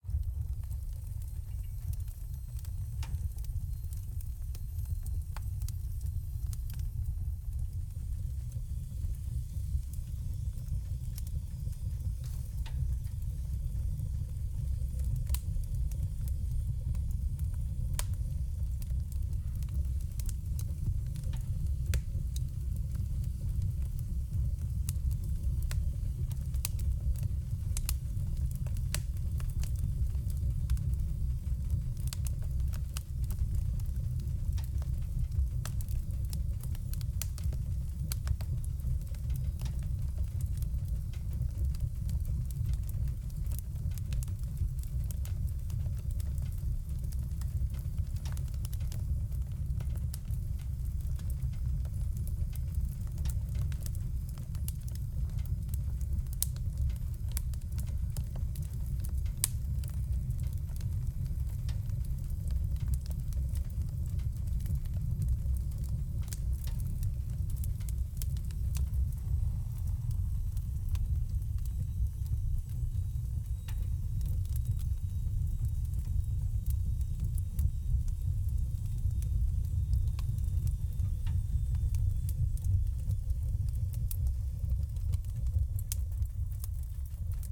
efx-sd-fire-in-metal-stove-03.ogg